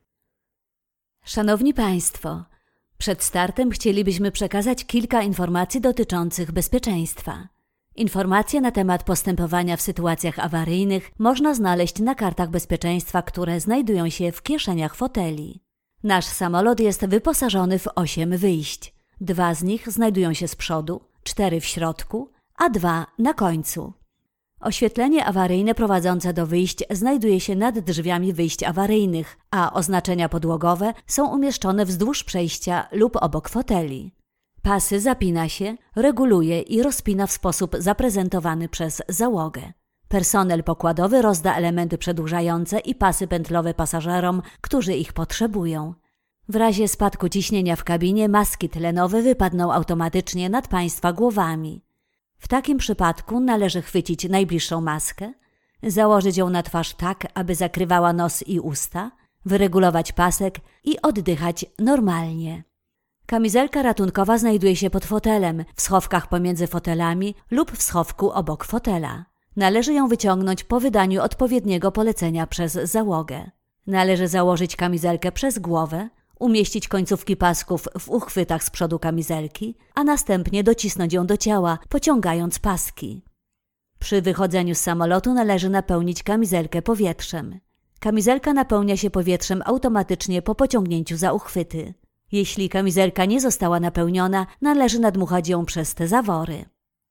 Sprecherin polnisch und Schauspielerin.
Sprechprobe: Industrie (Muttersprache):
I´m a professional native polish actress voice talent.